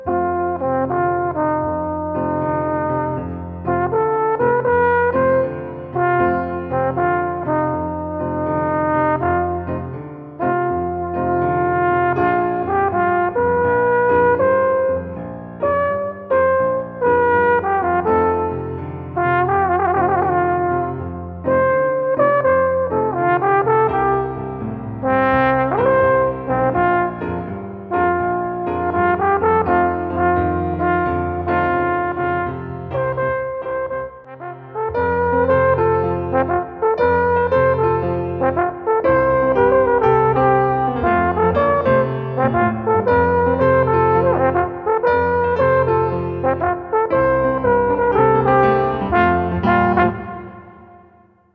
Kompositionen Alphorn (Solo bis Trio) mit Klavier